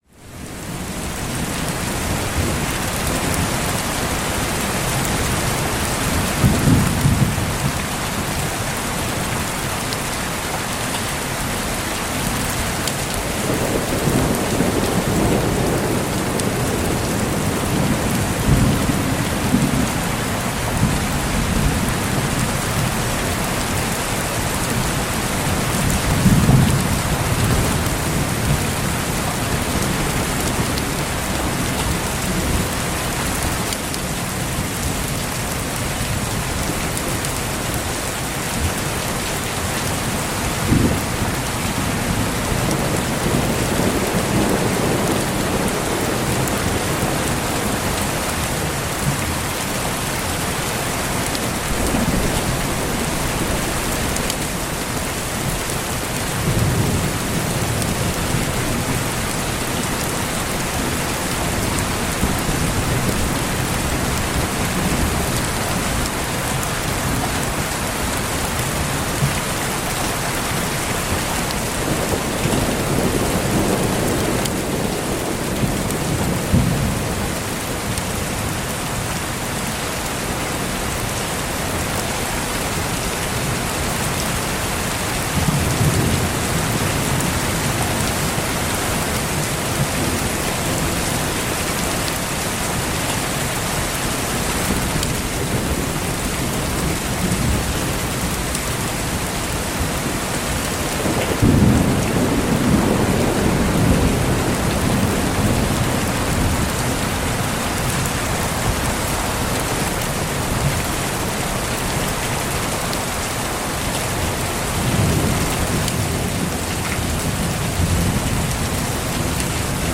Pluie intense et tonnerre maîtrisé pour une ambiance sonore relaxante